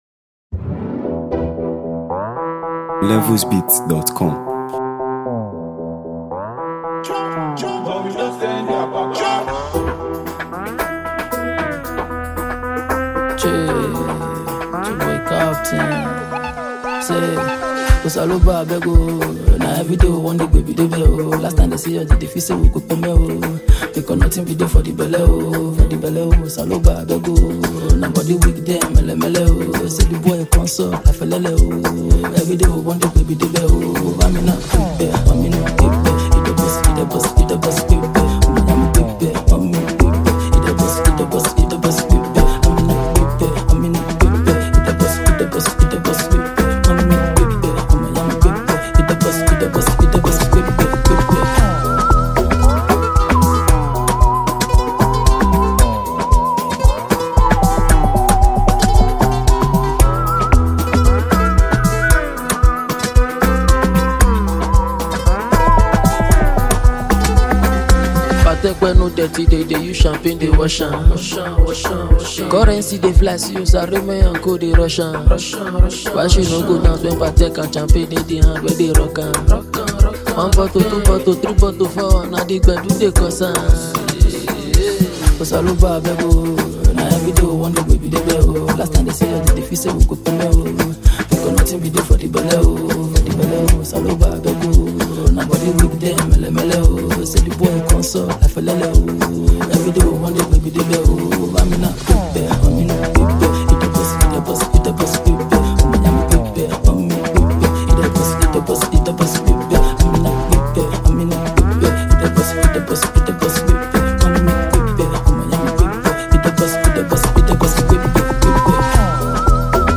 This energetic record
dynamic vocal delivery